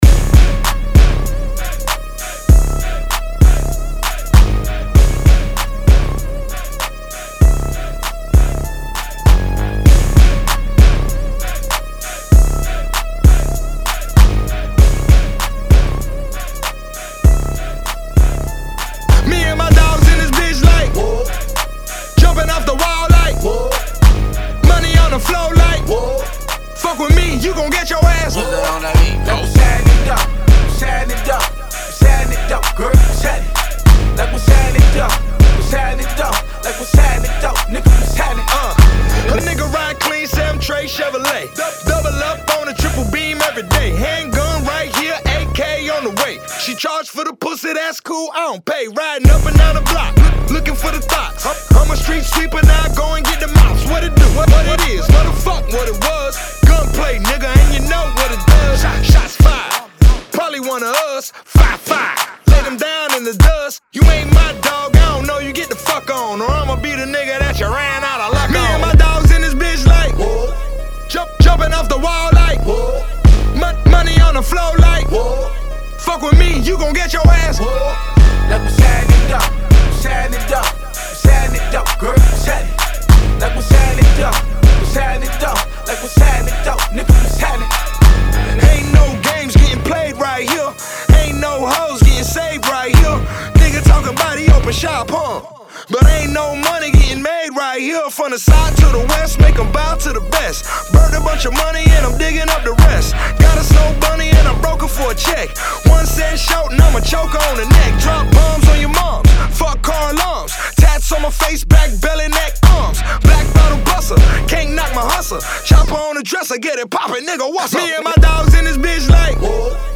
WESTCOAST BANGER